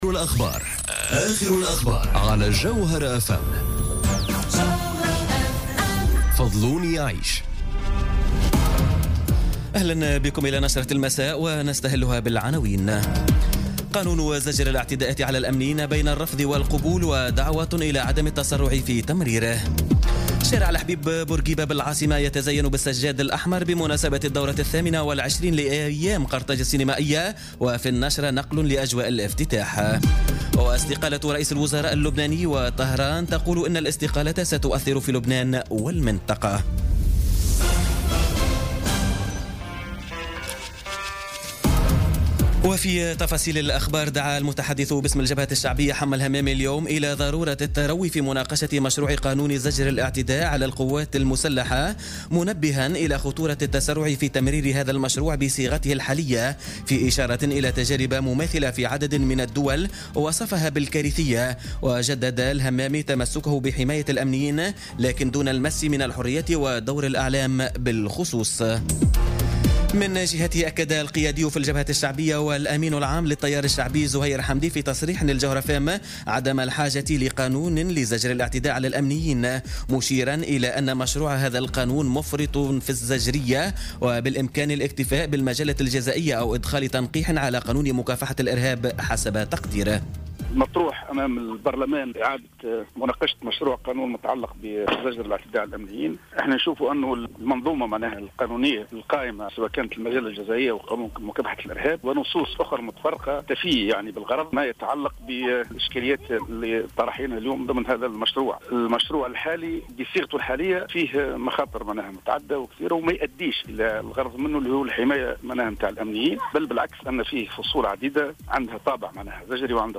نشرة أخبار السابعة مساء ليوم السبت 04 نوفمبر 2017